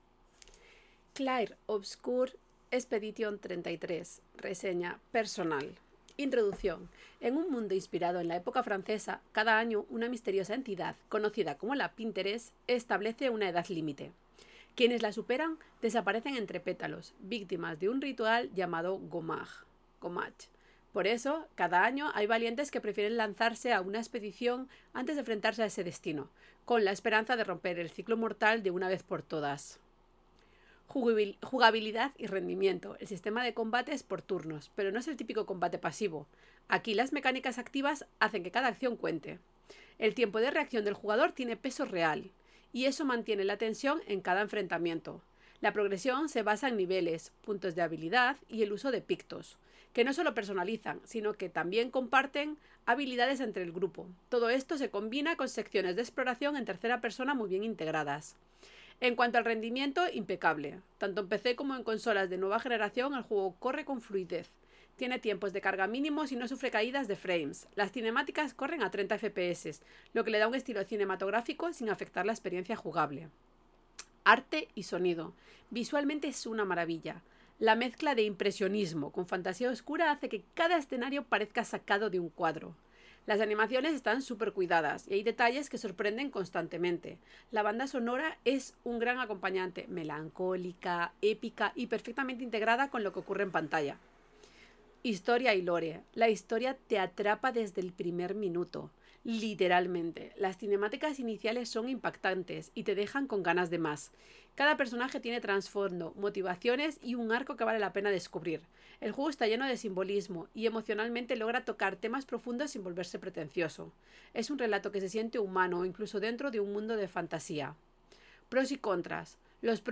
Reseña personal en audio: